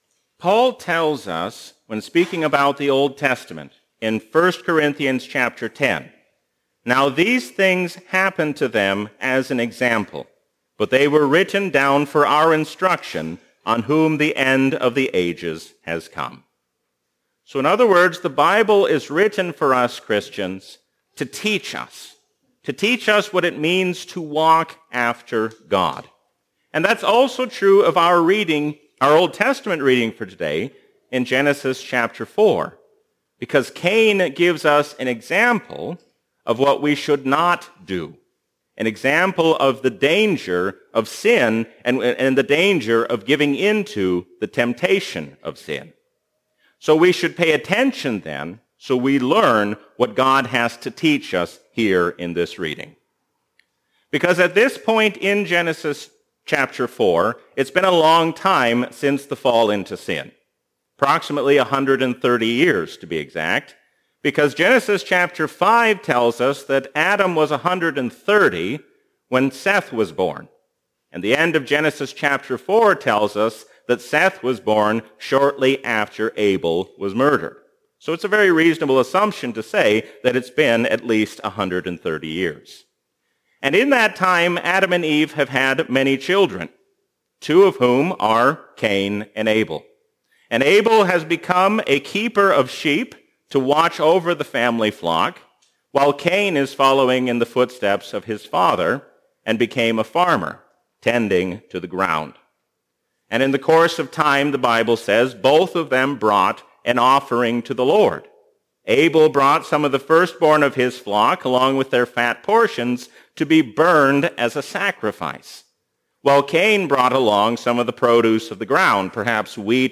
A sermon from the season "Easter 2024." Do not give in to the allure of this world, but stand fast, knowing that God will soon bring all evil to an end.